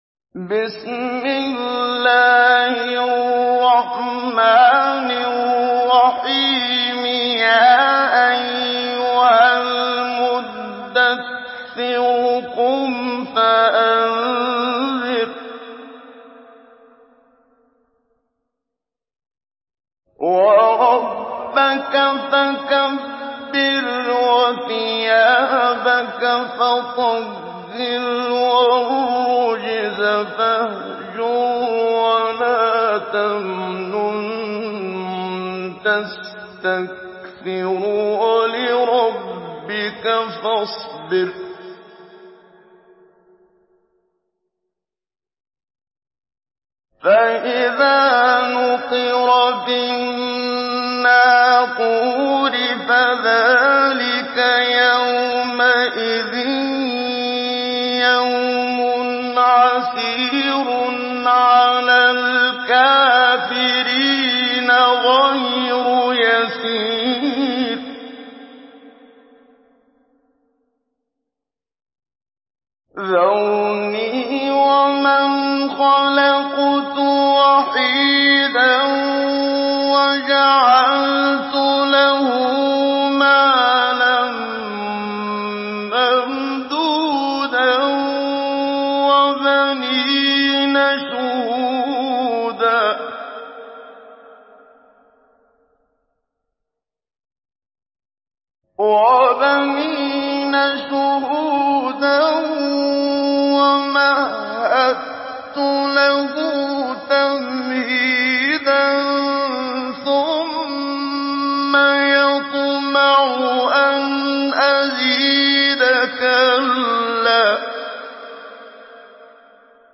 Surah المدثر MP3 by محمد صديق المنشاوي مجود in حفص عن عاصم narration.